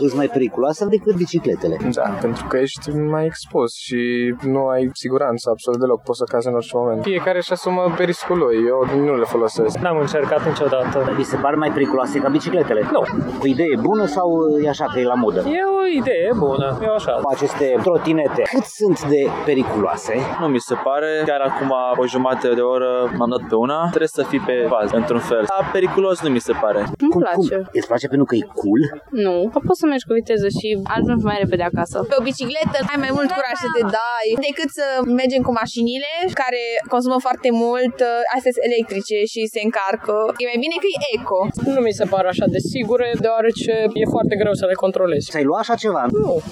Unii târgumureșeni spun că ar folosi trotinetele electrice doar pentru distracție, nu ca mijloc de transport permanent, iar alții cred că trotinetele pot fi mai periculoase decât bicicletele: